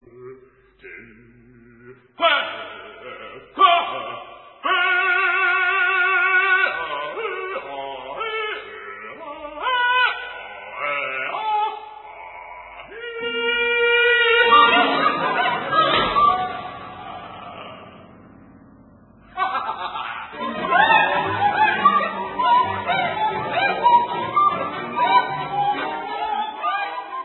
Avantgarde (1/1)